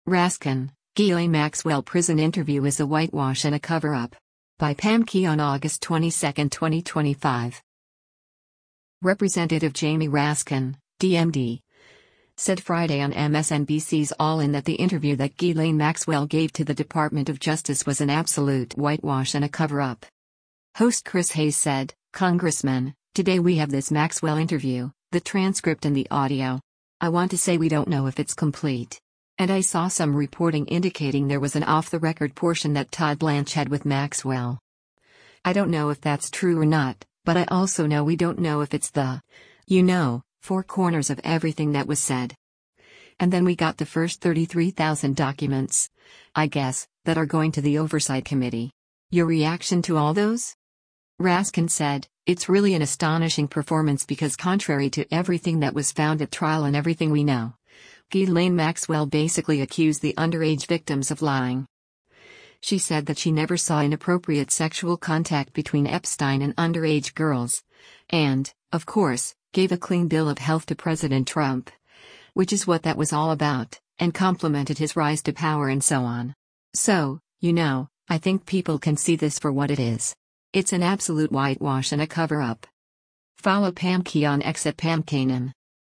Representative Jamie Raskin (D-MD) said Friday on MSNBC’s “All In” that the interview that Ghislaine Maxwell gave to the Department of Justice was an “absolute whitewash and a cover up.”